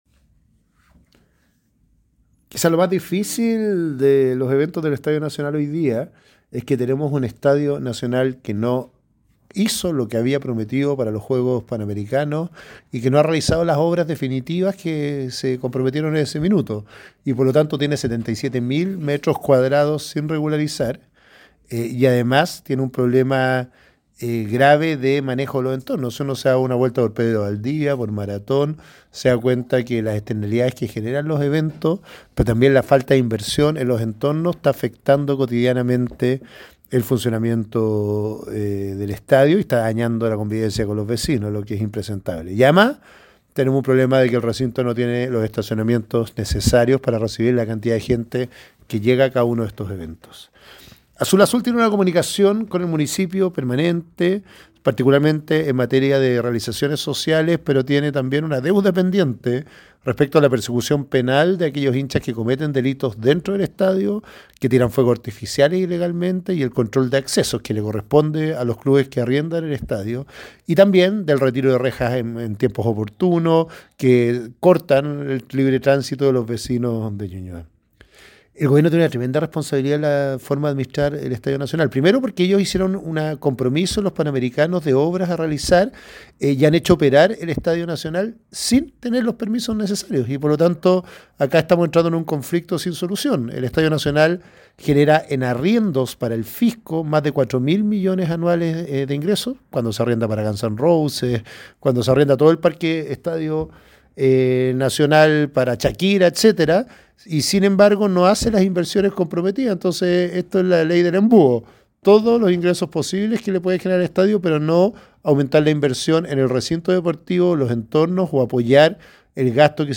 Sebastián Sichel, alcalde de Ñuñoa, en diálogo con ADN Deportes